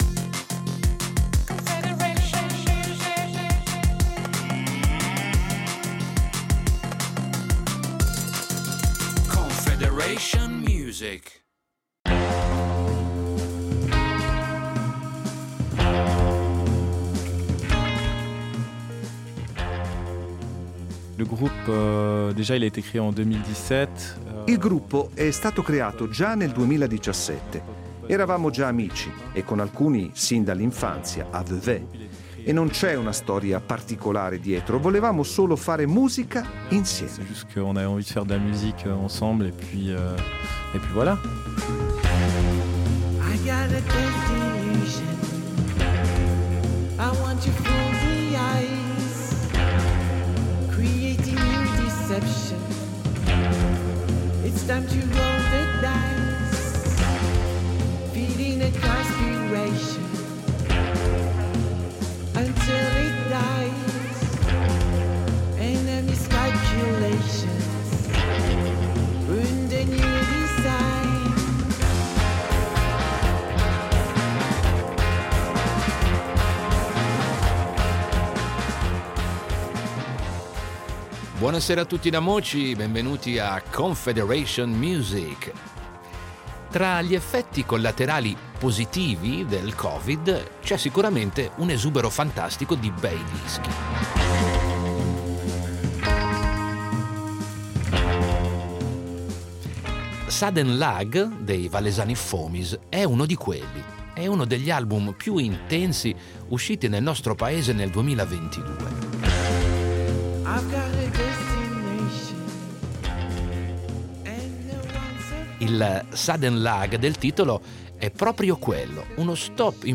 Dentro c’è un vortice kraut, fuzzy, progressivo e pieno ritmo.
E sebbene il fuzz dritto in faccia e l’attitudine punk degli esordi oggi lasci spazio anche alle sofisticazioni, alla psichedelia e a strutture più complesse, il quintetto di Vevey mantiene la sua deliziosa e cruda autenticità.